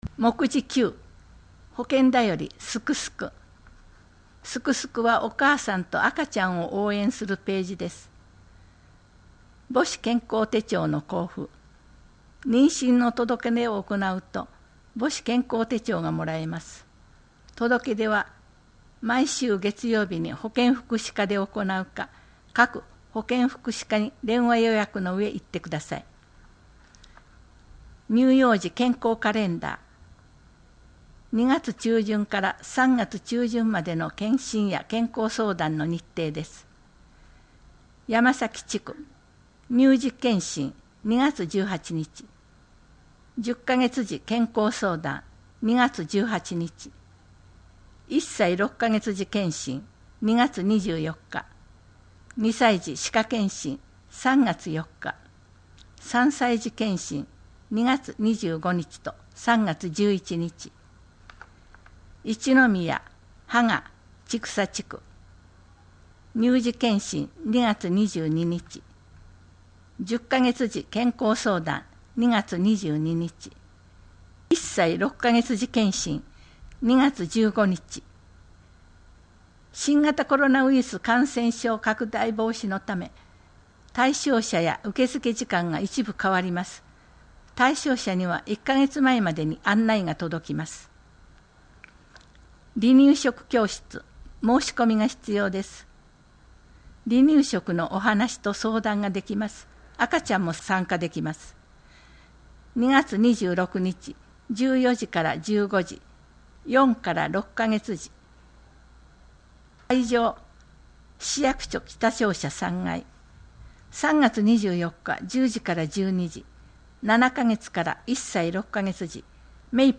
このページでは、広報しそうの記事が録音された「声の広報」が楽しめます。